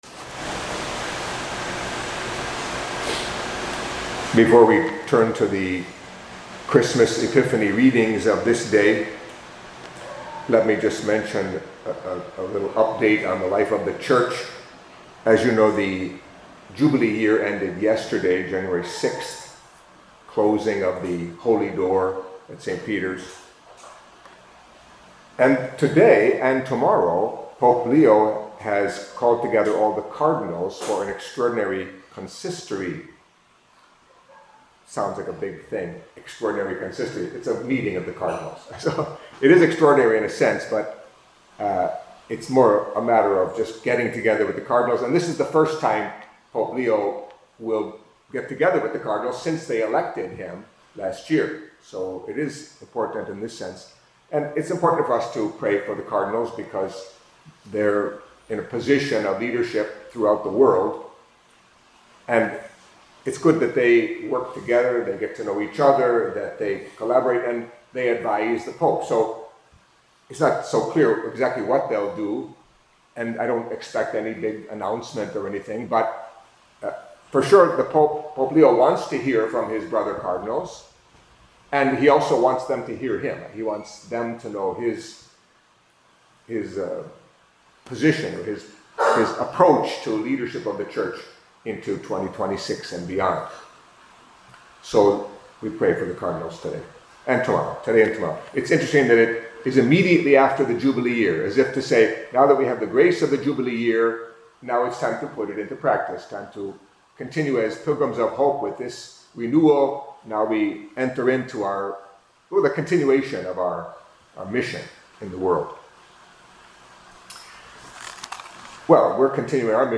Catholic Mass homily for Wednesday After Epiphany